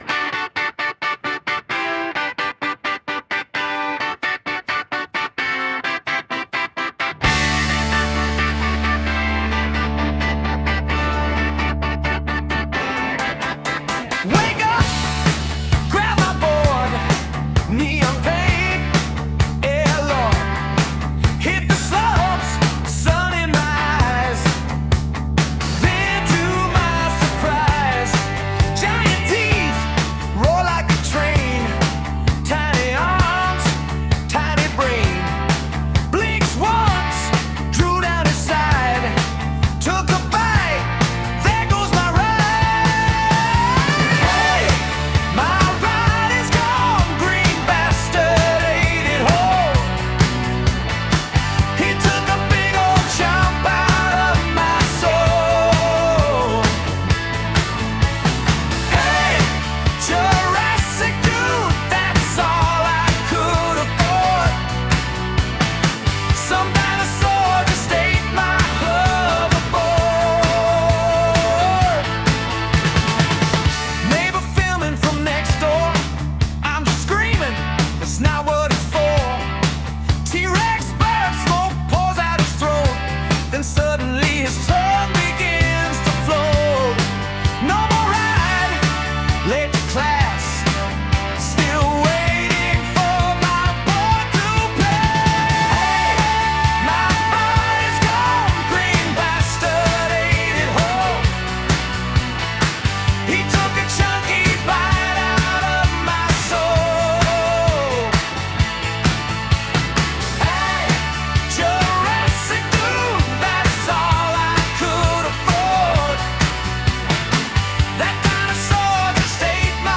(song by Suno)